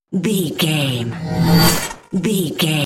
Sci fi appear whoosh debris
Sound Effects
futuristic
whoosh